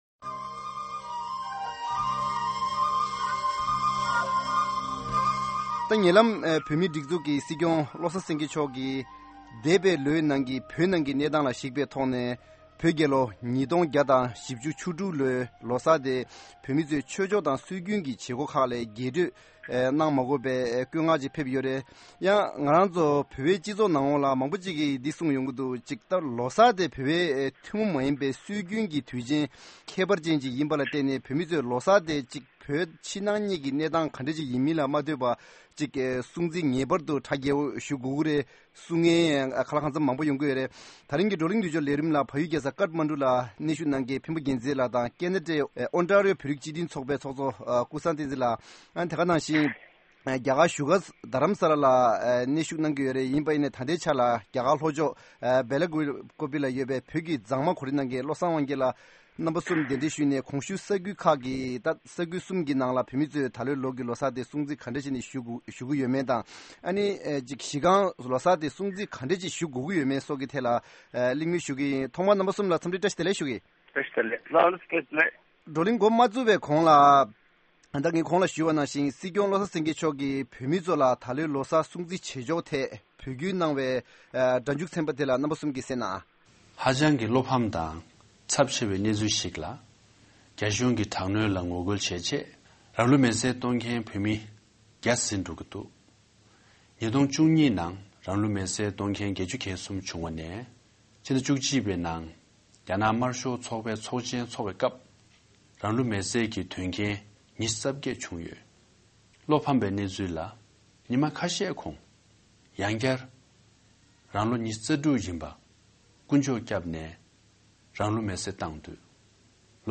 བལ་ཡུལ་དང་རྒྱ་གར་ དེ་བཞིན་ ཀེ་ན་ཊ་བཅས་ལ་གནས་བོད་མི་གསུམ་ལ་ ལོ་གསར་སྲུང་བརྩི་བྱེད་ཕྱོགས་ཐད་གླིང་མོལ་ཞུ་གི་ཡིན།